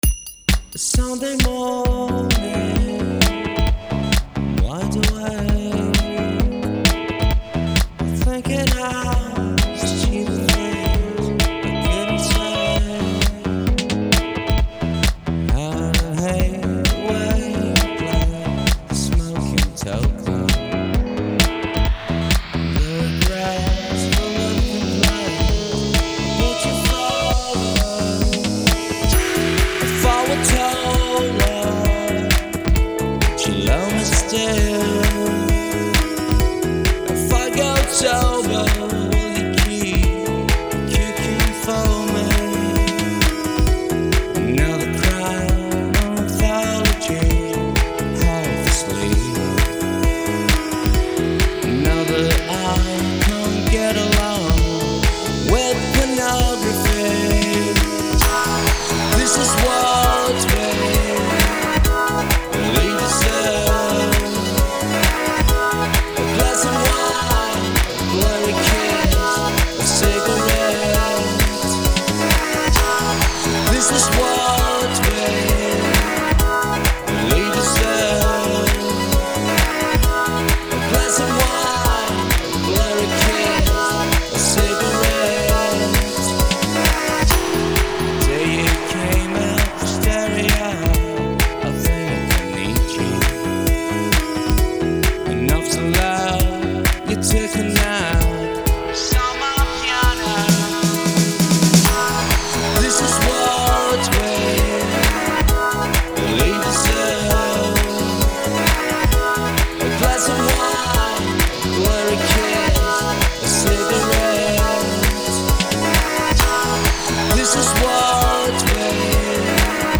diventa un pezzo ballabilissimo